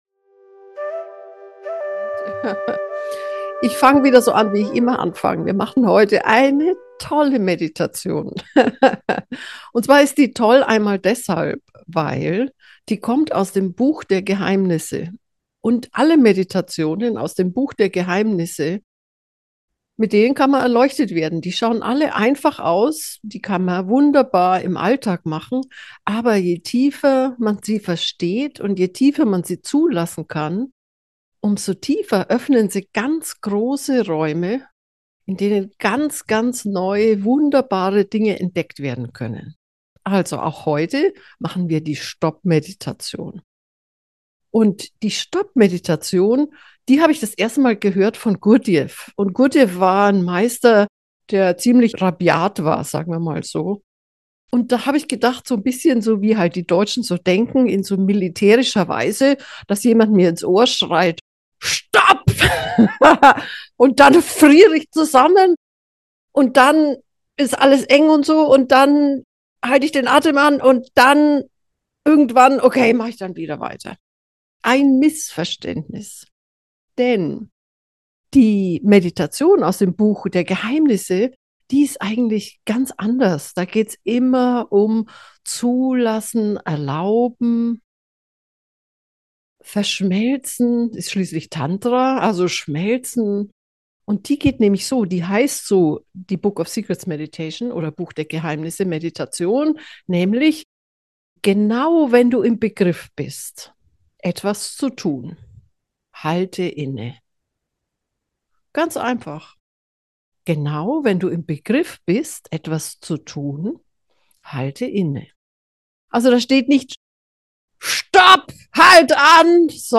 Diese geführte Meditation aus dem Buch der Geheimnisse von Osho hat eine große Kraft.
Folge 257: Innehalten – Geführte Meditation